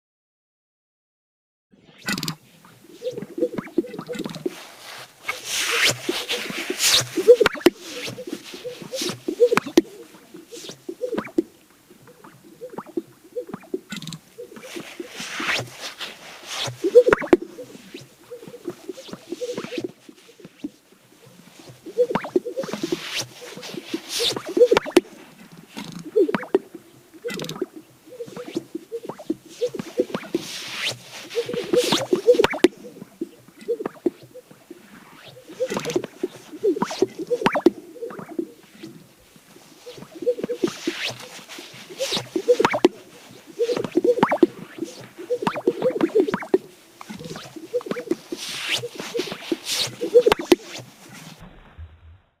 Mating calls at the lek:
Male sage-grouse
Sagegrouse.m4a